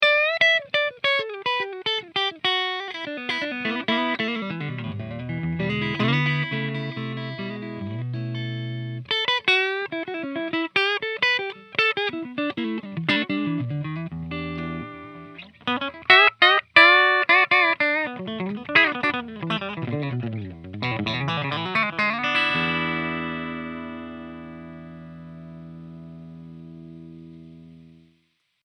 Country riff 2